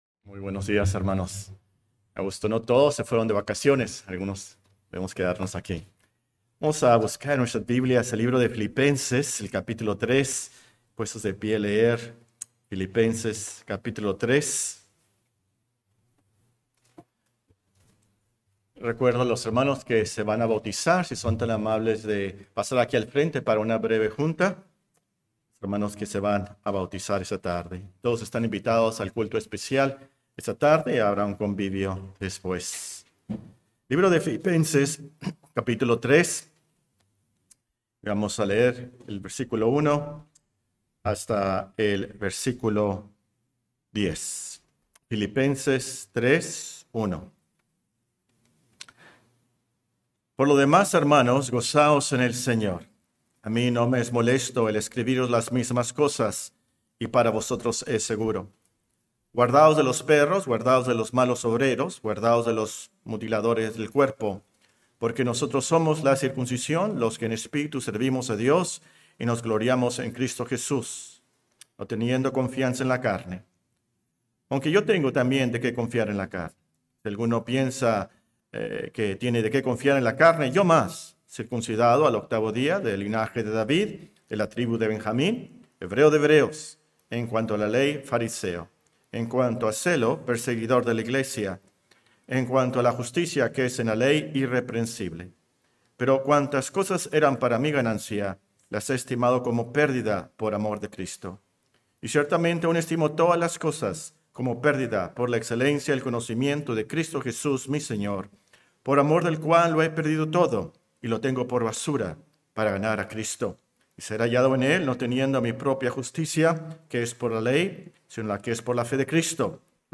Serie de sermones Resurrección de Jesús